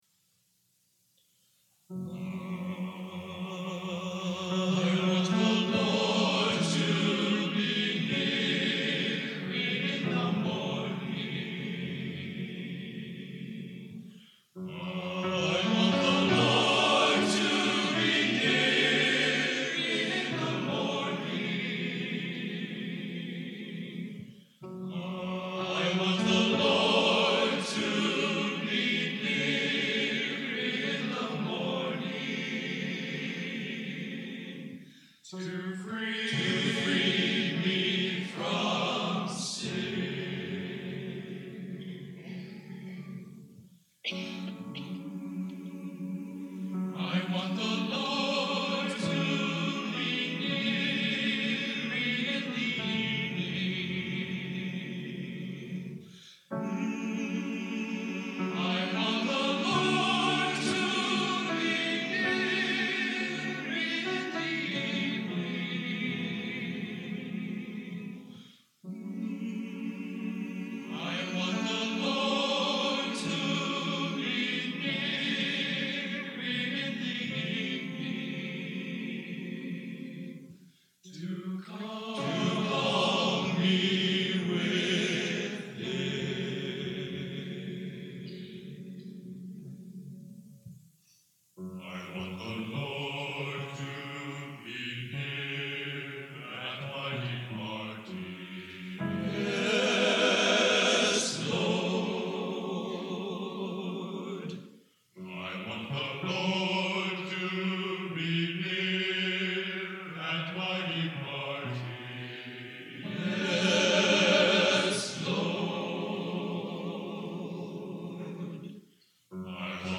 Genre: Classical Sacred | Type: